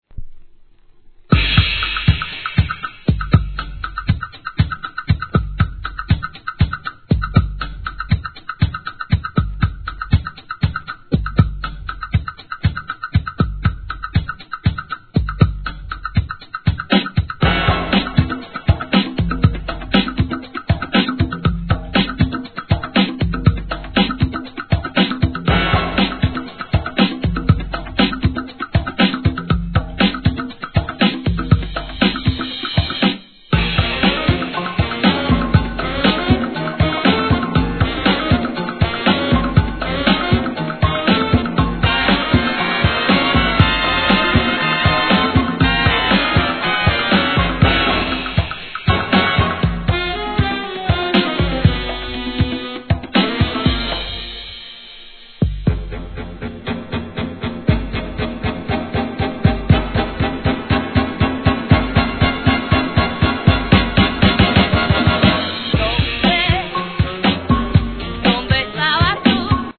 1. HIP HOP/R&B